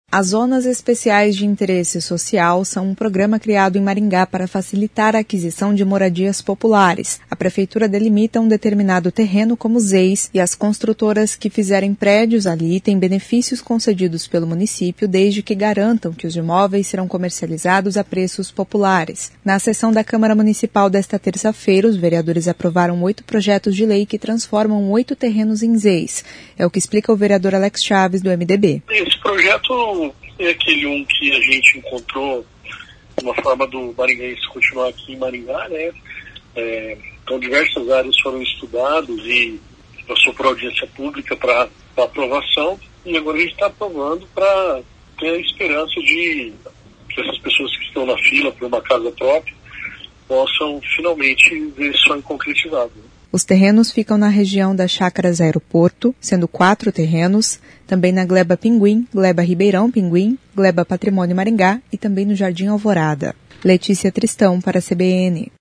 Na sessão desta terça-feira (28), a Câmara aprovou oito projetos de lei que transformam oito terrenos em Zeis, explica o vereador Alex Chaves (MDB).